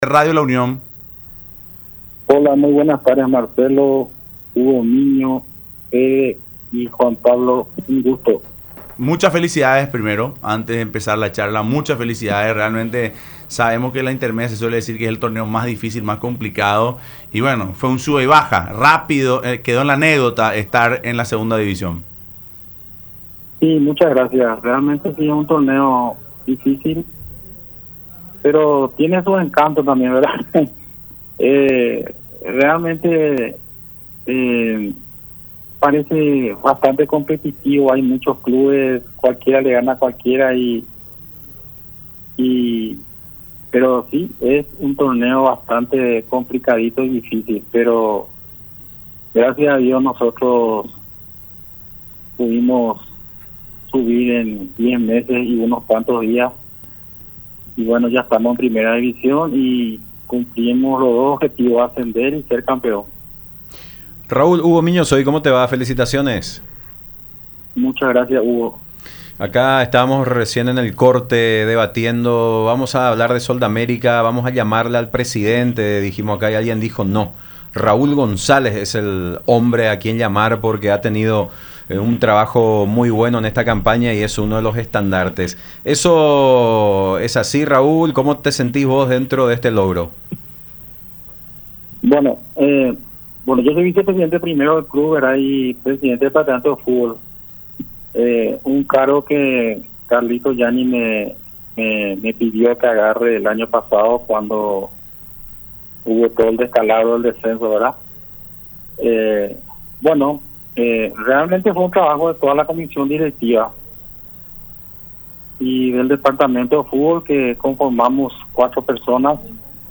En conversación con el equipo de Fútbol Club, a través de radio la Unión y Unión TV, explicó que se logró los objetivos que se trazaron a inicios de año. Afirmó que se tuvo que luchar muchísimos para conseguirlas por lo difícil que es jugar la Intermedia.